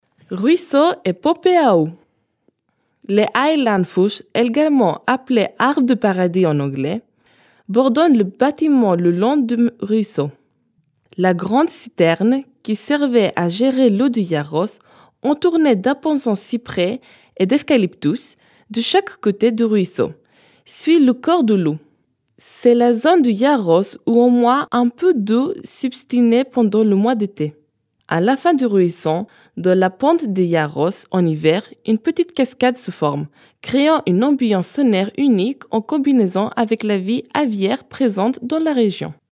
C’est la seule zone de Gyaros où au moins un peu d’eau subsiste pendant les mois d’été. À la fin du ruisseau, dans les pentes de Gyaros, en hiver, une petite cascade se forme, créant une ambiance sonore unique en combinaison avec la vie aviaire présente dans la région.